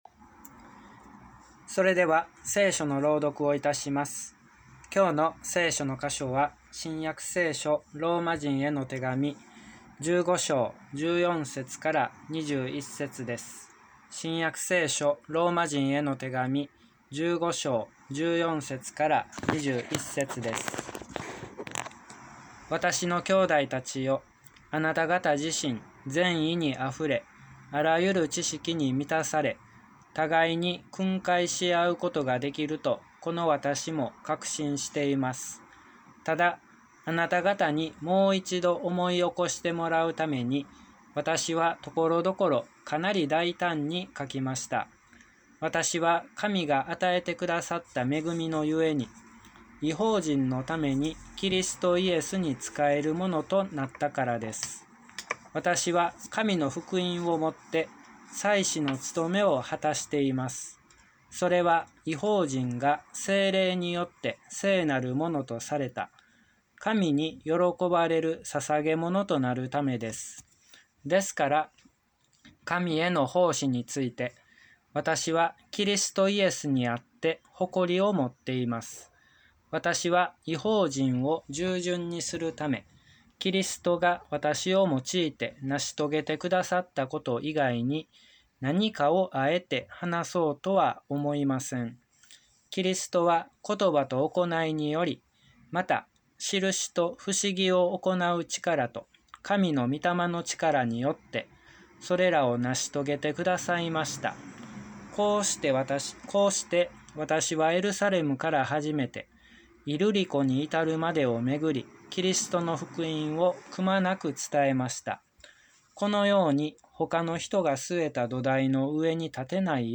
礼拝説教から ２０２１年９月５日